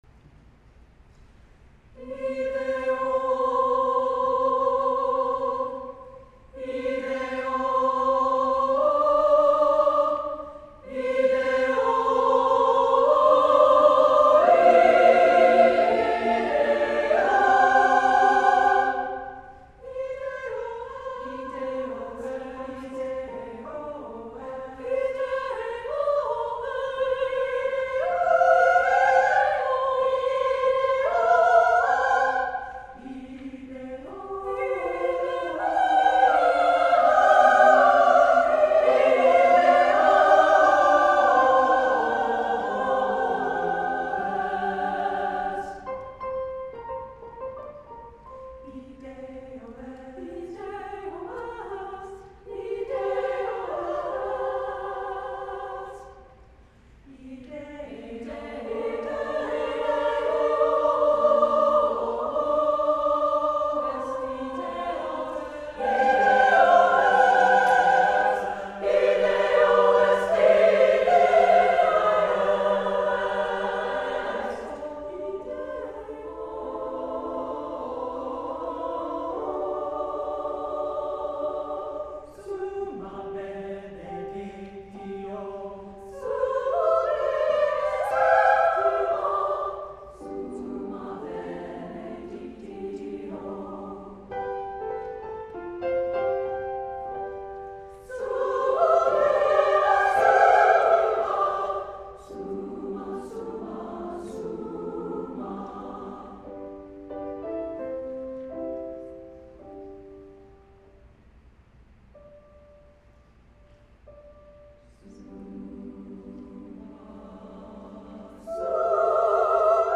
SSAA (4 voix égales de femmes) ; Partition complète.
Sacré ; Profane
exubérant ; joyeux ; élogieux
Vibraphone (1) ; Marimba (1) ; Piano (1)
sol majeur ; do mineur ; sol mineur ; mi majeur